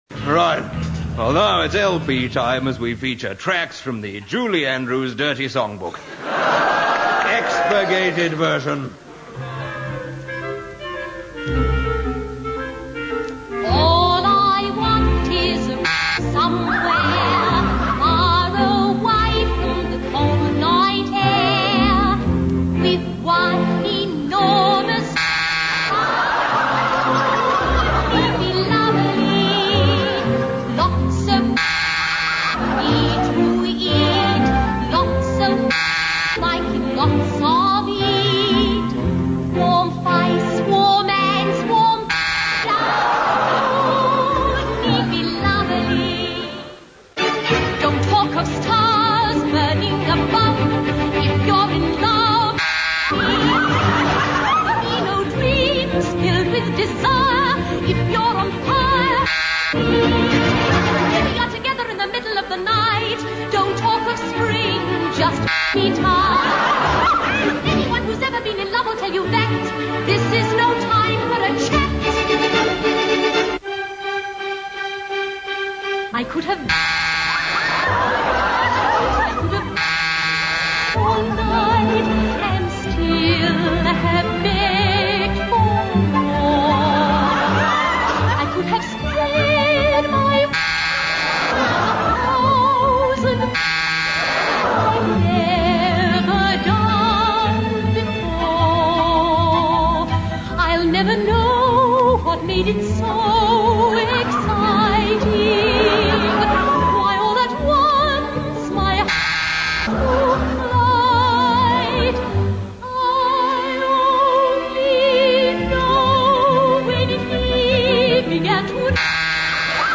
It was radio sketch comedy, complete with recurring characters whose entrances always inspired enormous cheers from the studio audiences.